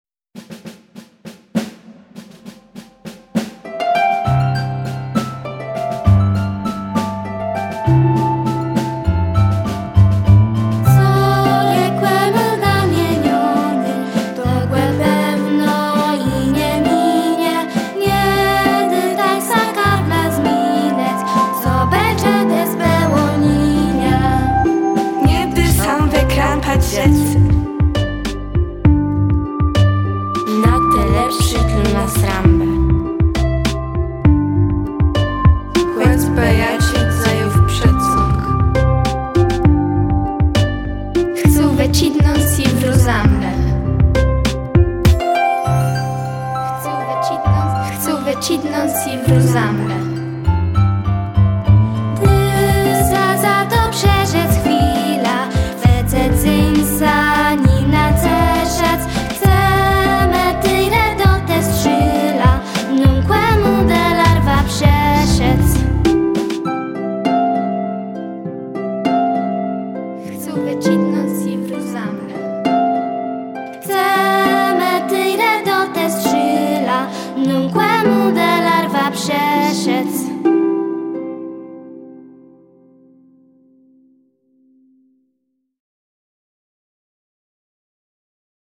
Nagranie wykonania utworu tytuł